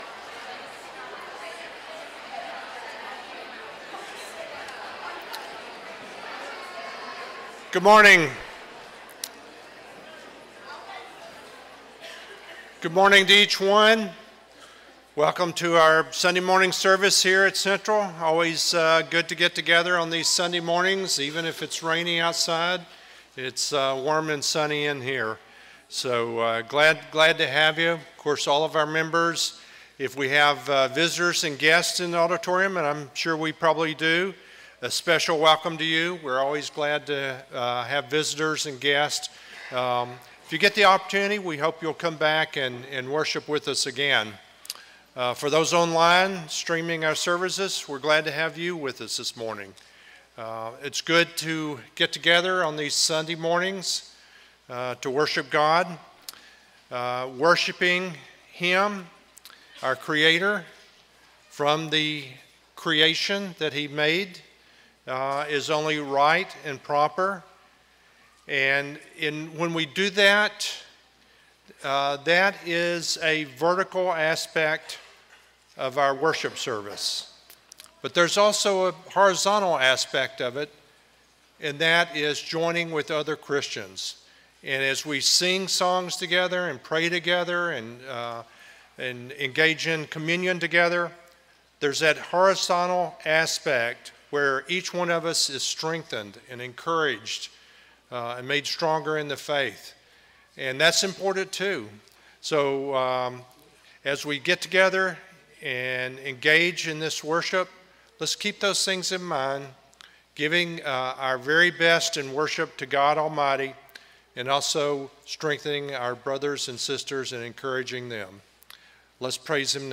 Romans 12:17-19, English Standard Version Series: Sunday AM Service